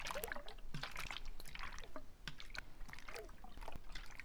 idleSlosh_r8b.wav